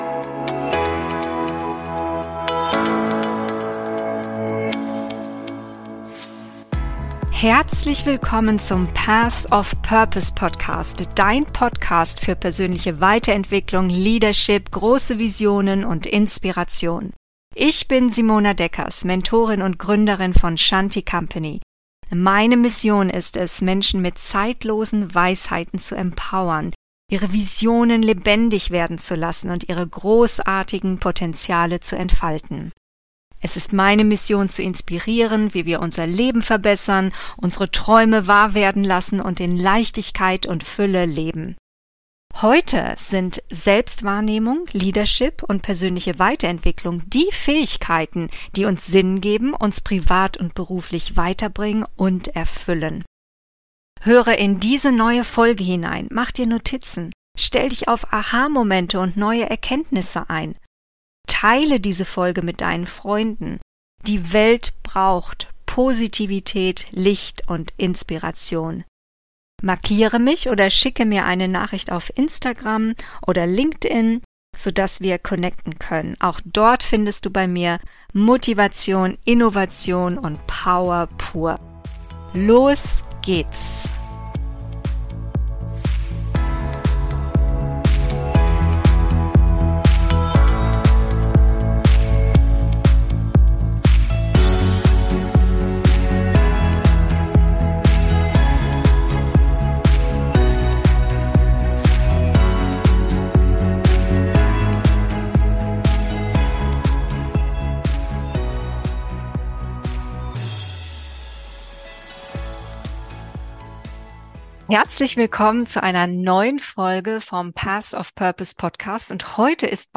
Lebenselixier Emotionen: So bist Du wirklich DU im Job – Interview